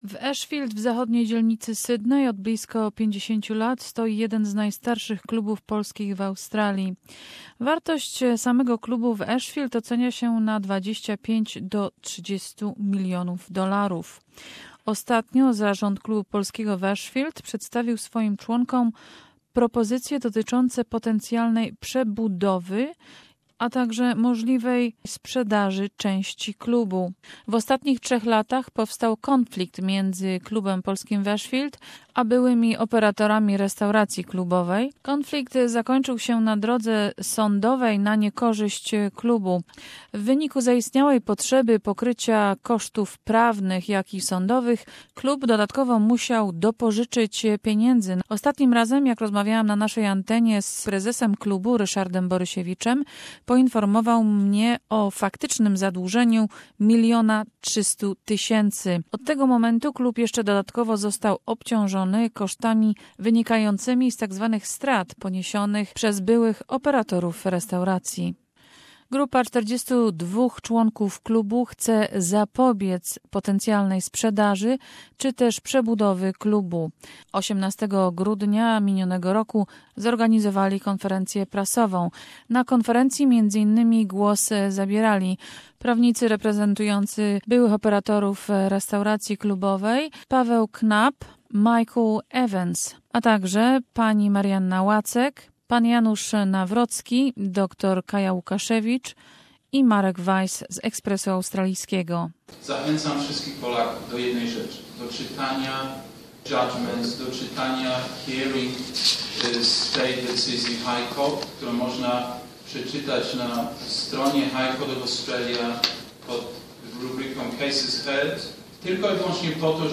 Report from Polish Club in Ashfield, Sydney.